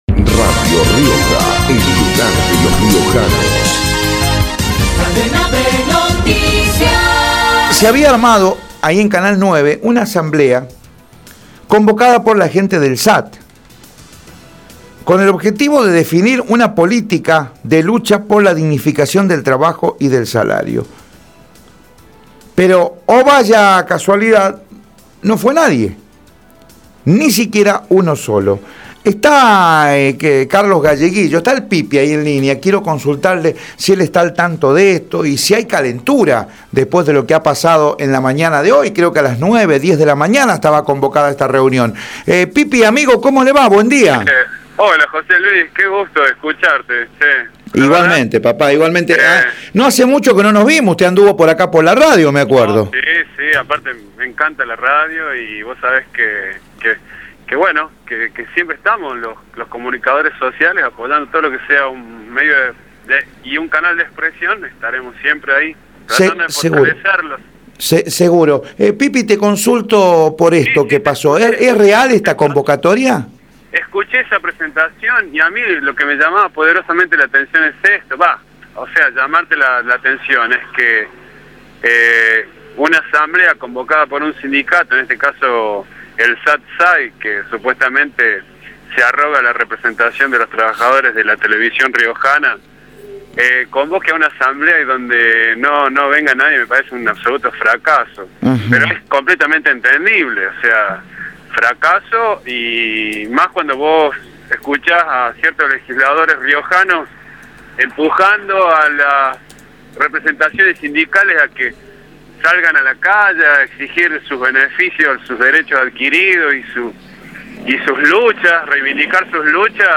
por Radio Rioja